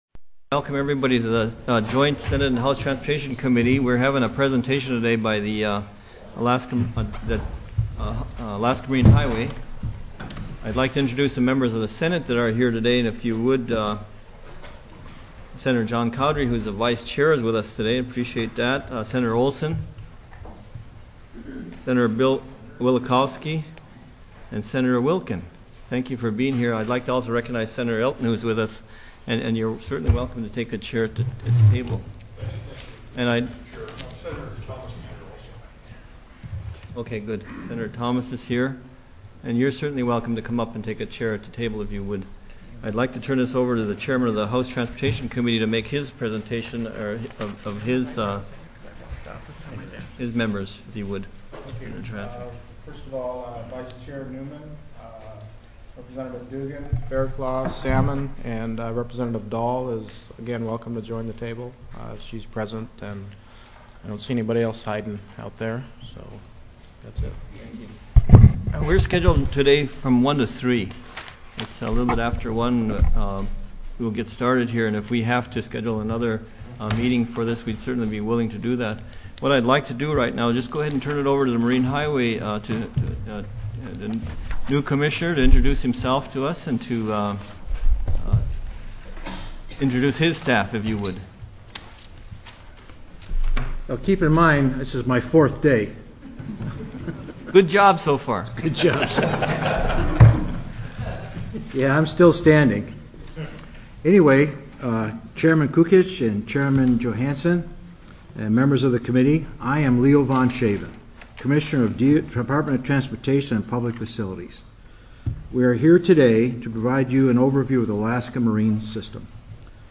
Joint with Senate Transportation TELECONFERENCED